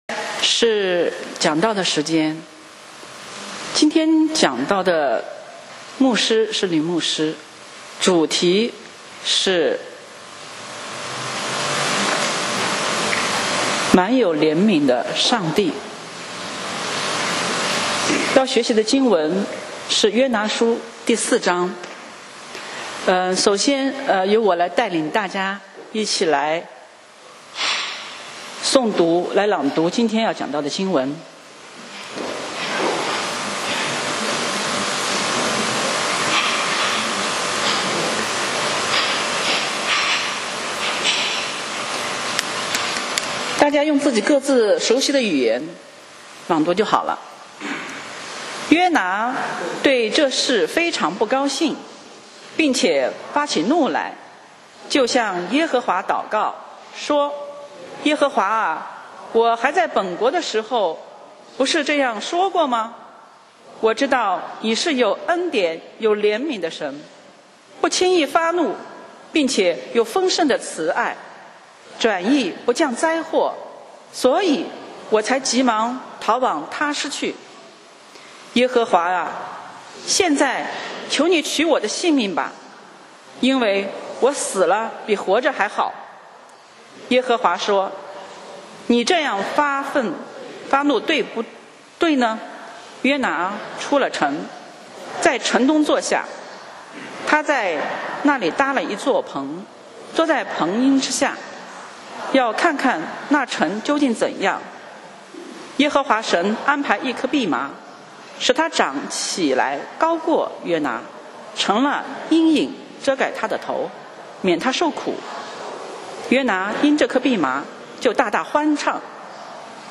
講道 Sermon 題目 Topic：满有怜悯的上帝 經文 Verses：约拿书 4章. 1这事约拿大大不悦，且甚发怒。